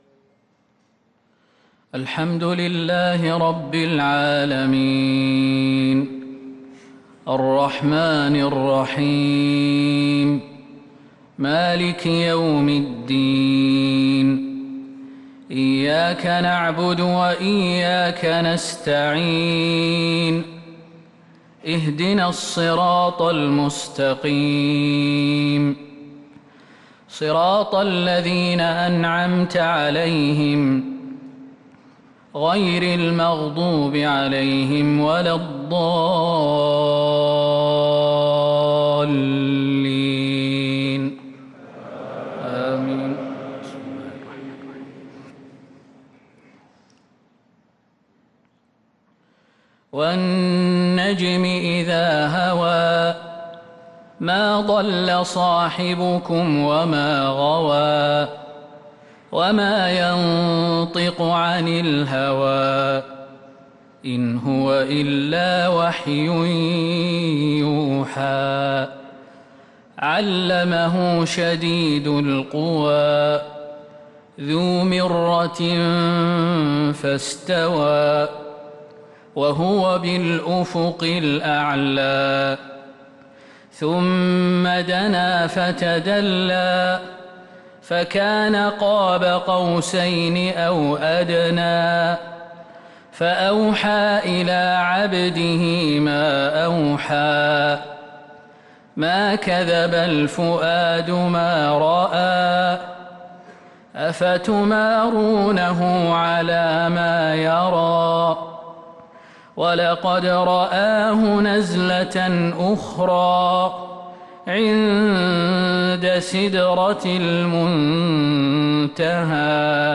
صلاة الفجر للقارئ خالد المهنا 17 ذو القعدة 1443 هـ
تِلَاوَات الْحَرَمَيْن .